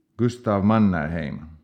Baron Carl Gustaf Emil Mannerheim (Finland Swedish: [(kɑːrl) ˈɡʉstɑːf (ˈeːmil) ˈmɑnːærˌheim]